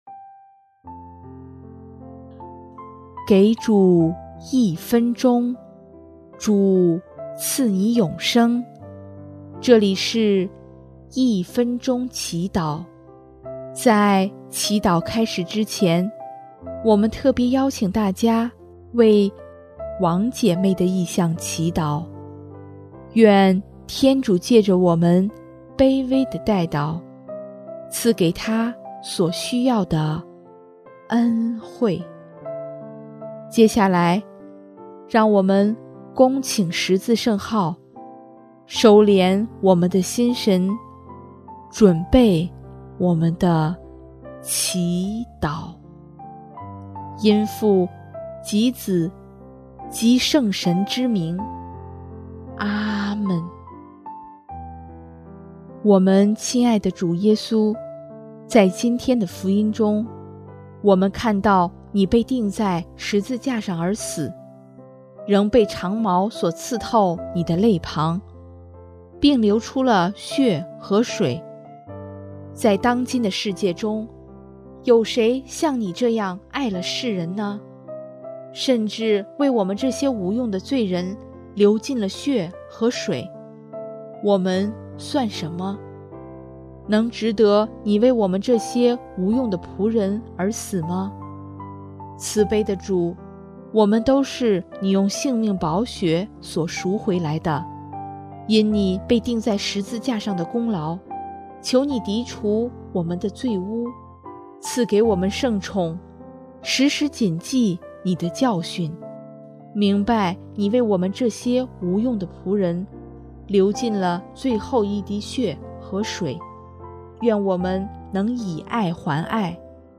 【一分钟祈祷】|6月7日 用主的性命宝血赎回了我们